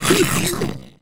sounds / monsters / fracture / hit_1.ogg
hit_1.ogg